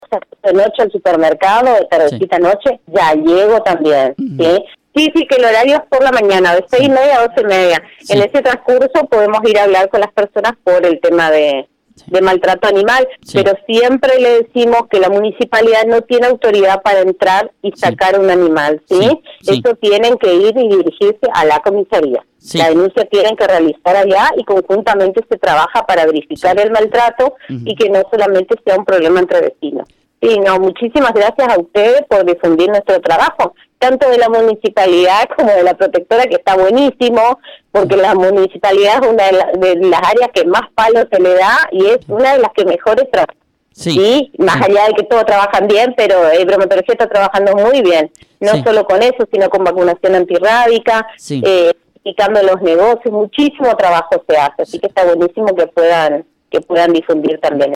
En charla telefónica